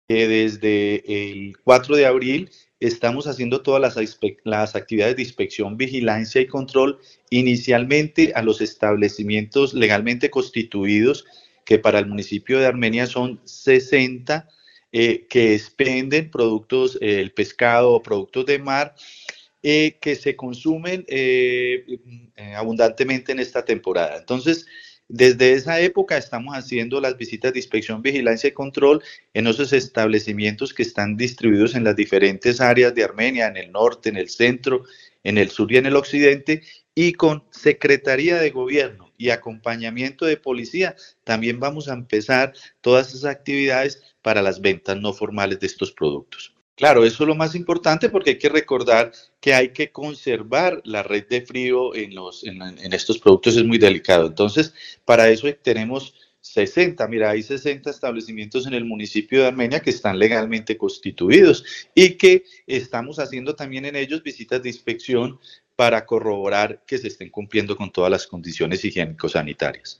Secretario de Salud de Armenia